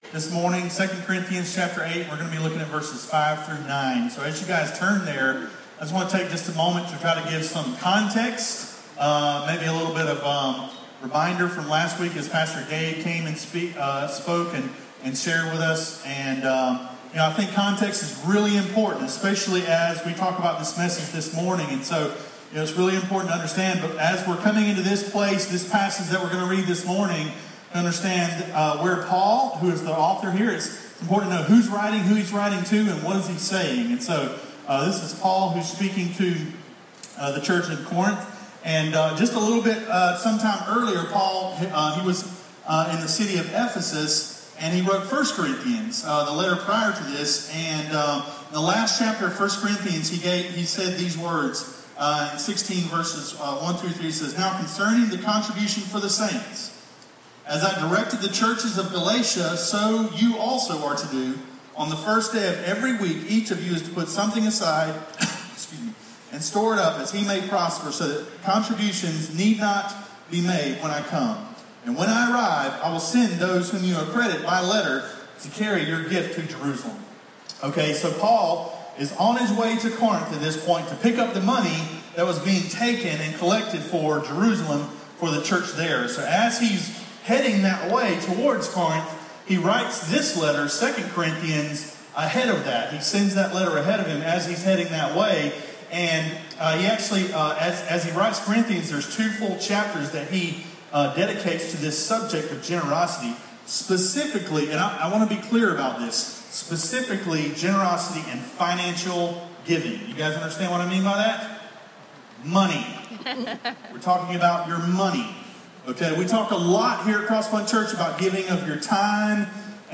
Audio of Sermon: A powerful sermon that every Church and every Christian needs to hear.